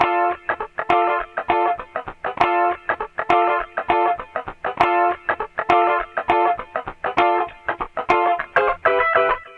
Sons et loops gratuits de guitares rythmiques 100bpm
Guitare rythmique 46